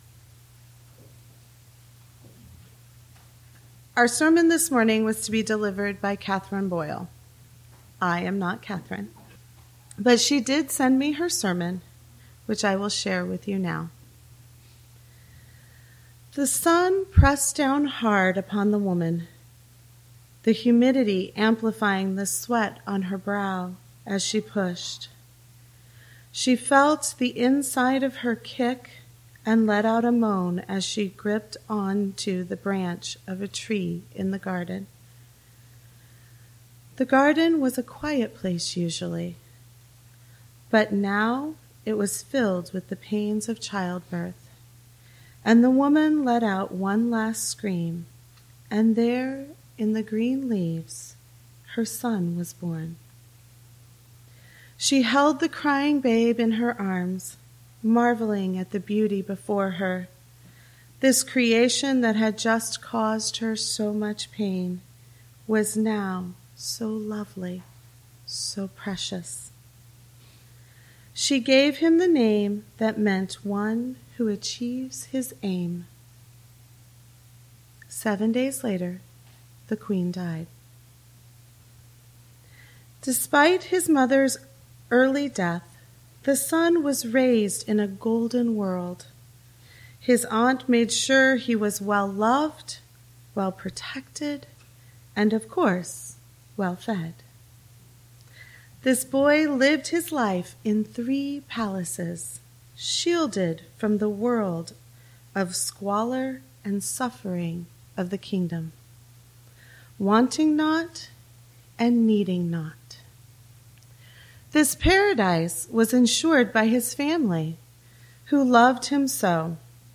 This sermon explores the concept of curiosity as a vital spiritual practice by contrasting superficial interests with a profound, focused pursuit of truth.